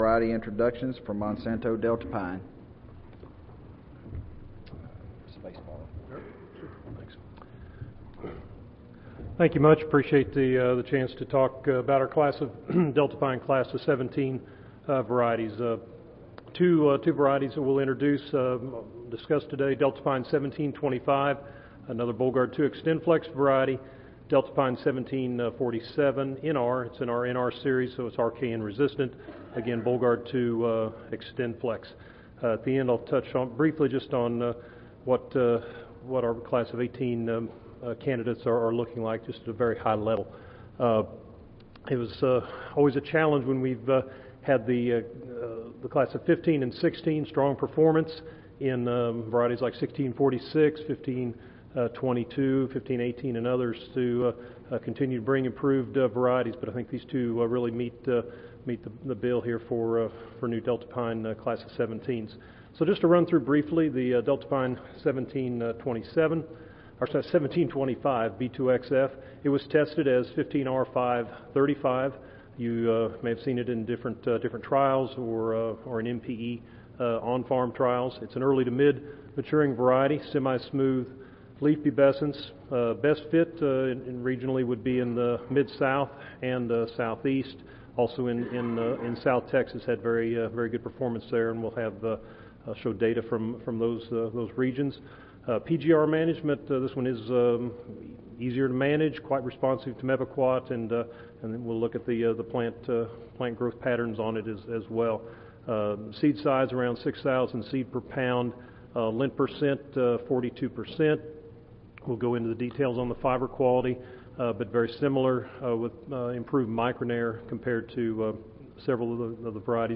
Monsanto Company Audio File Recorded Presentation Presentation will include description of the new Class of 17 variety agronomic and phenotypic characteristics, yield performance and fiber quality. Comparisons to current Deltapine and competitive checks will be used to discuss overall perfomance and fit.